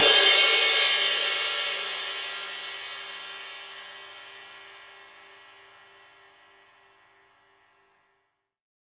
Q 20Crash.WAV